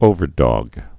(ōvər-dôg, -dŏg)